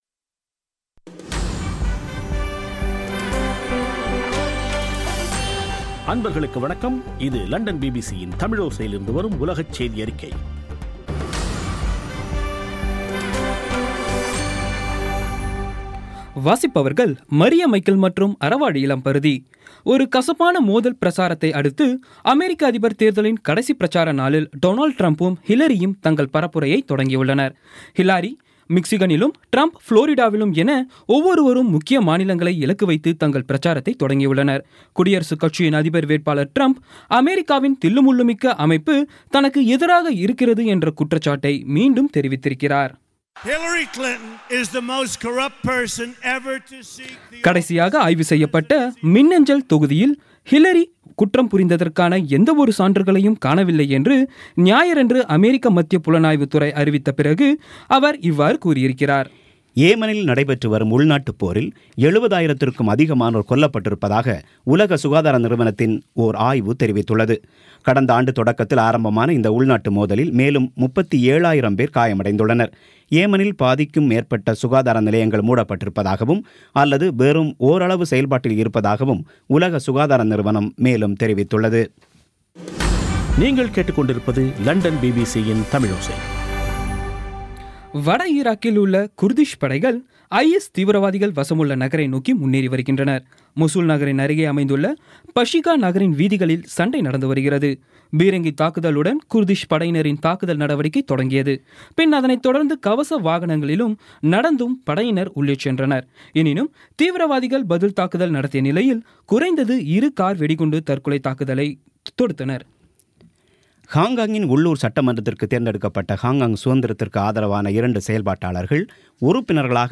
இன்றைய (நவம்பர் 7ம் தேதி ) பிபிசி தமிழோசை செய்தியறிக்கை